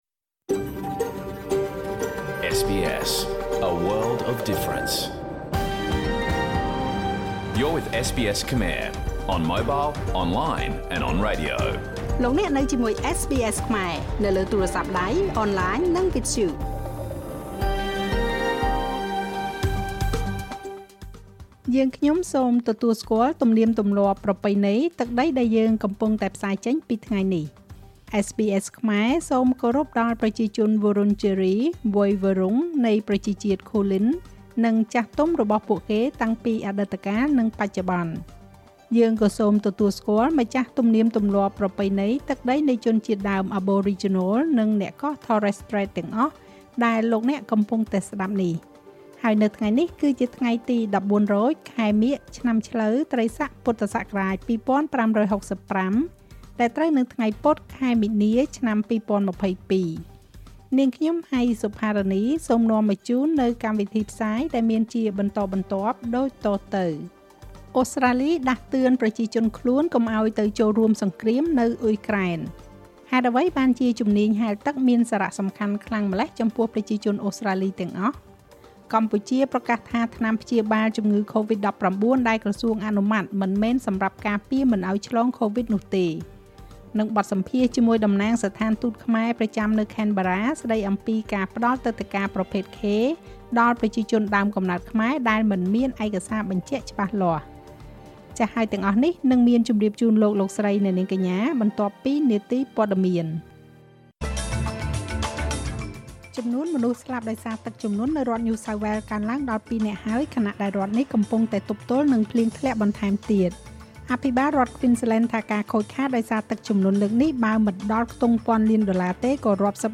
ការផ្សាយបន្តផ្ទាល់របស់ SBSខ្មែរនៅលើផតខាស្ត៍ សម្រាប់ថ្ងៃពុធ ទី ២ មីនា ឆ្នាំ២០២២
ស្តាប់ការផ្សាយបន្តផ្ទាល់របស់ SBS ខ្មែរ តាមវិទ្យុ តាមគេហទំព័រអនឡាញ និងតាមកម្មវិធី SBS Radio app។ ឥឡូវនេះអ្នកក៏អាចស្តាប់កម្មវិធីពេញរបស់យើងដោយគ្មានការផ្សាយពាណិជ្ជកម្មនៅលើផតខាស្ត៍របស់យើងផងដែរ។